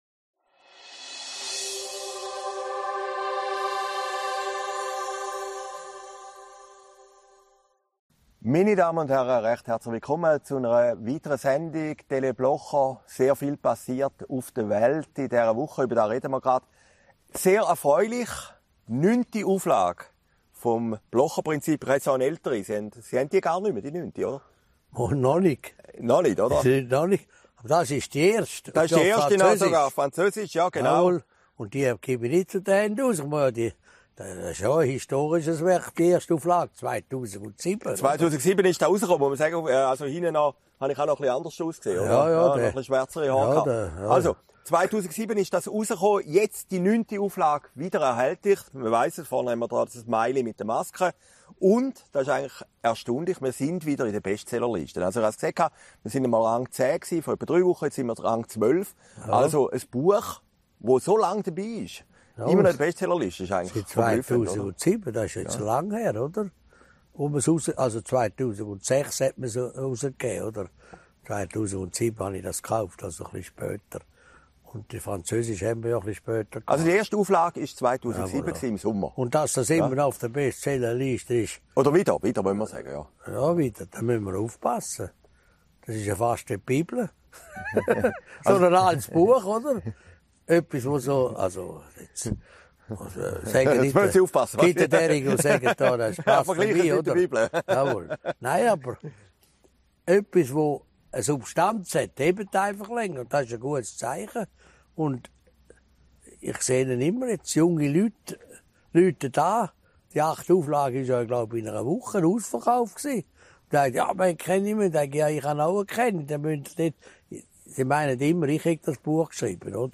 Sendung vom 20. August 2021, Herrliberg